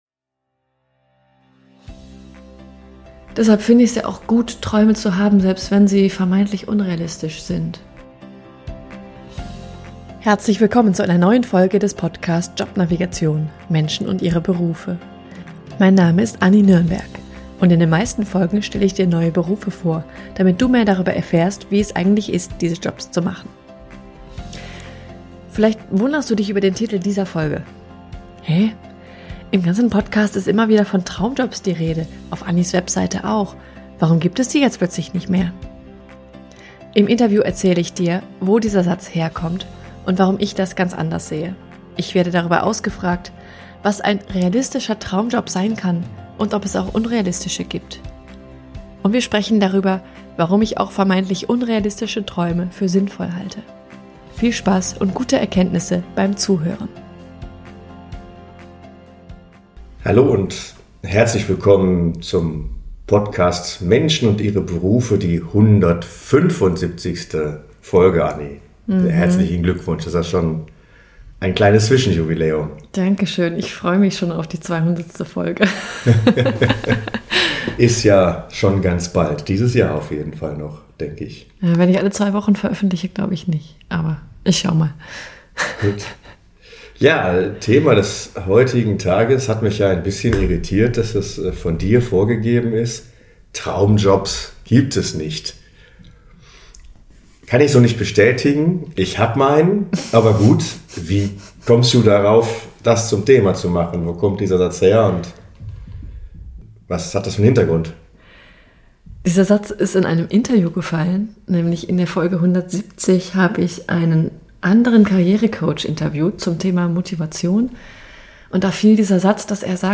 Ich werde darüber ausgefragt, was ein realistischer Traumjob sein kann und ob es auch unrealistische gibt. Und wir sprechen darüber, warum ich auch vermeintlich unrealistische Träume für sinnvoll halte.